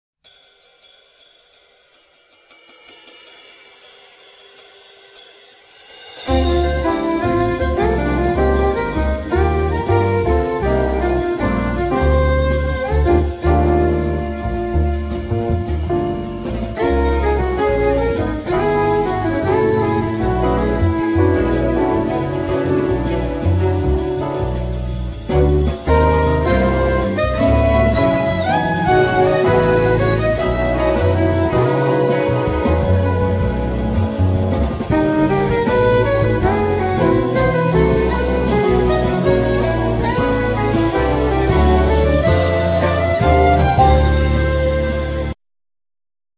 Acoustic Violin,Electric Violin,Tenor Violin
Piano,French horn
Double Bass
Drums
Percussion
Violin,Viola,Cello,Arco Bass